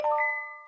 chime_1.ogg